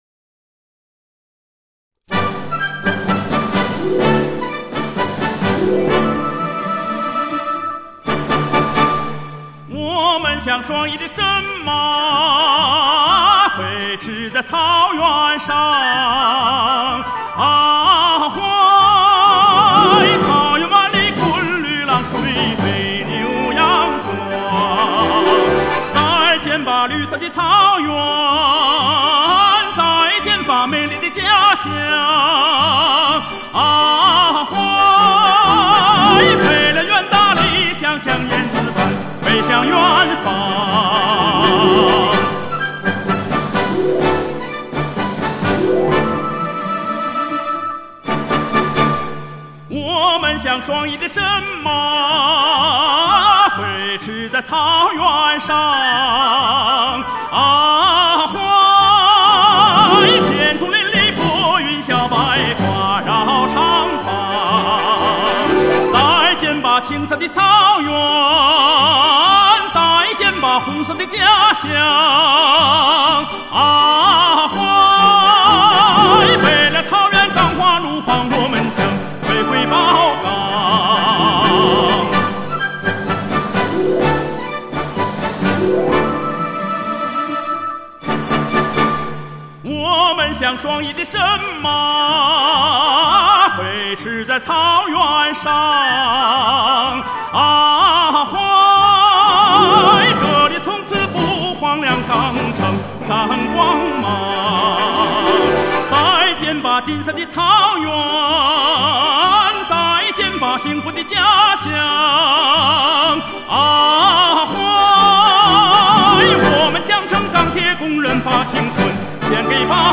震撼人心的旋律与演唱激起了我们对祖国的自豪感。
MP3/128k(低品质)